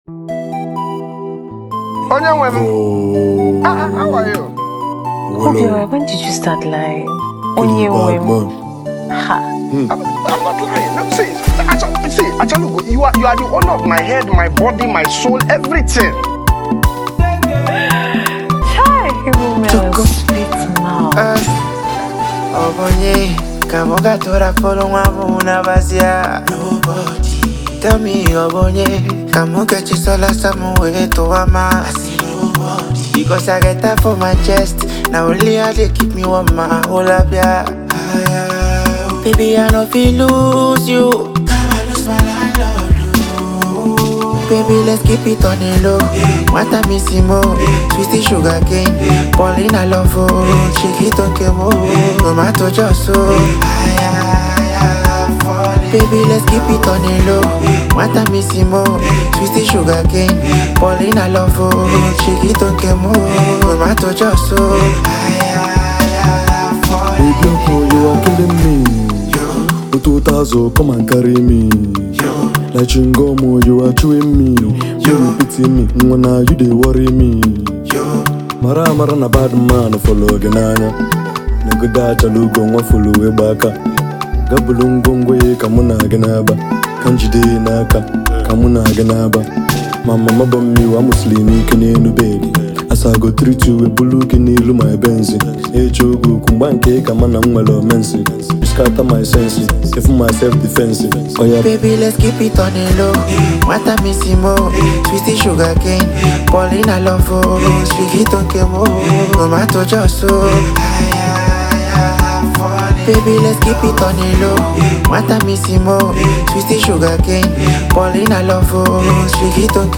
native hip-hop, Trap